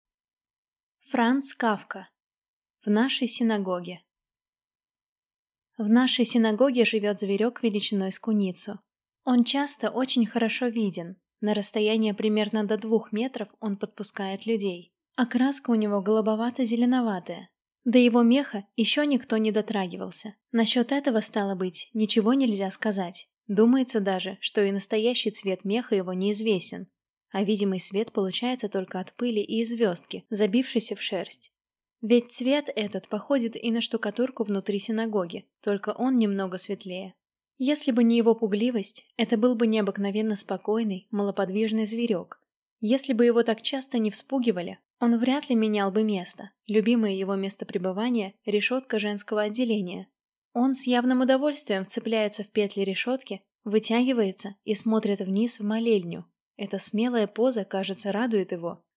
Аудиокнига В нашей синагоге | Библиотека аудиокниг